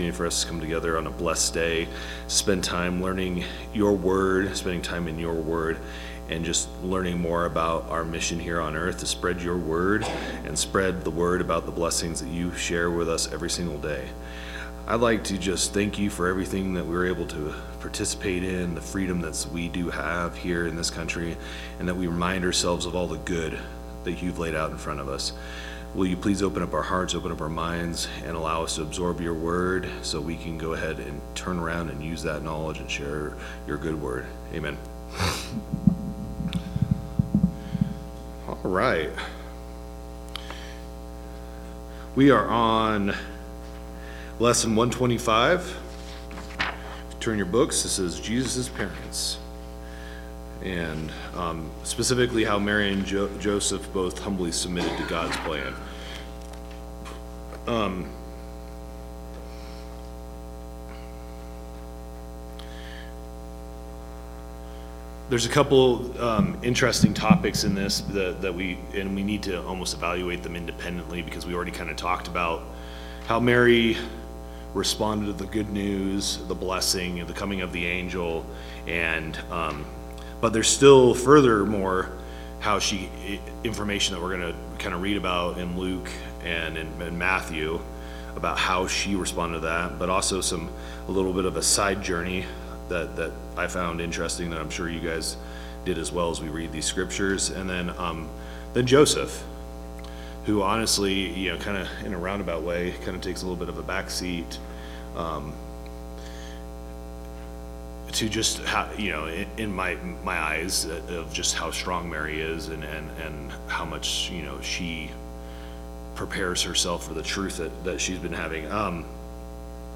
Bible Class 02/01/2026 - Bayfield church of Christ